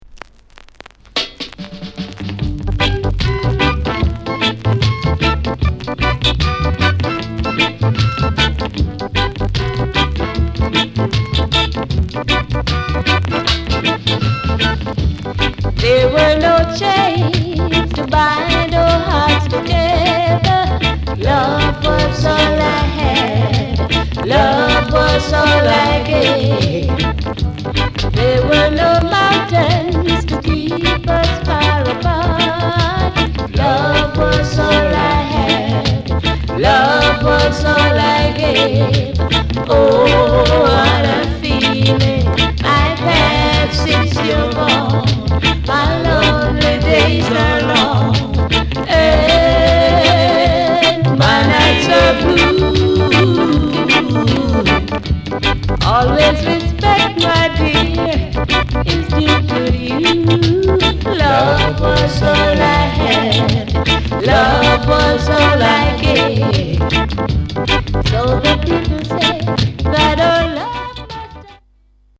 REGGAY